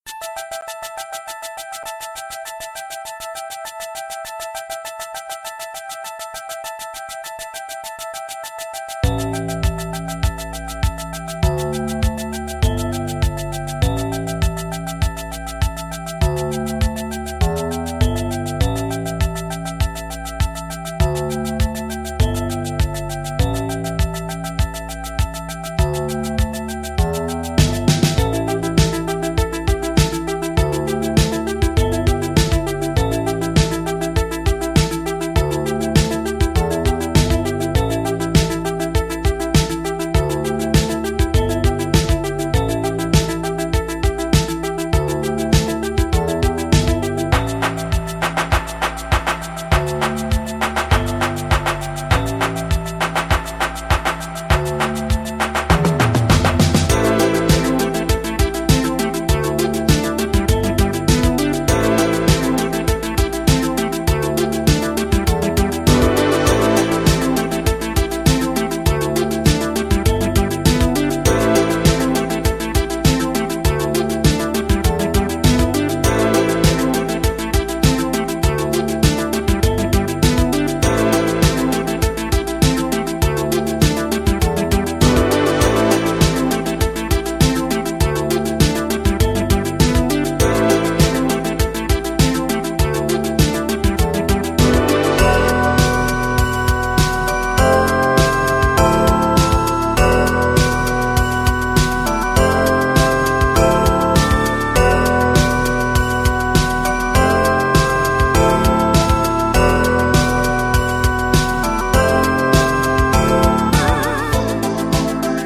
Electro Techno Wave